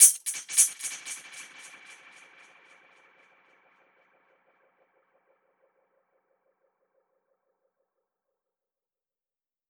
Index of /musicradar/dub-percussion-samples/85bpm
DPFX_PercHit_C_85-09.wav